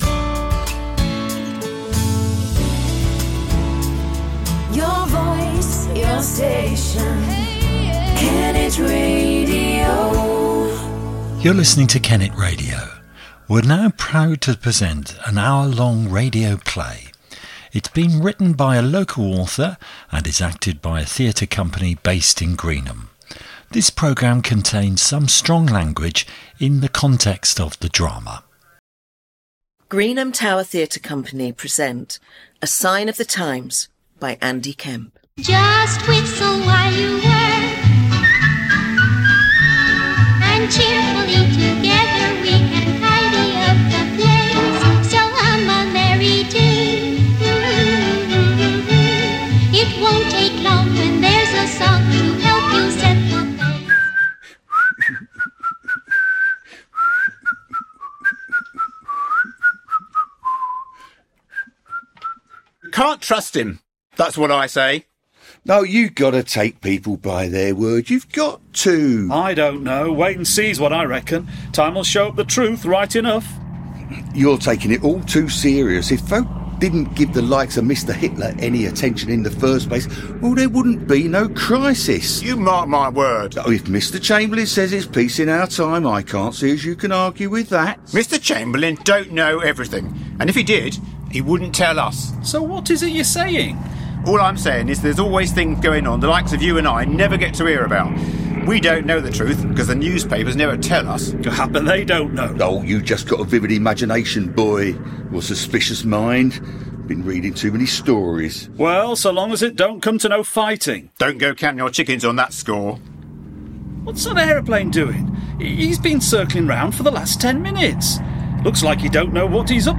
PODCAST: “A Sign Of The Times” radio play based in Greenham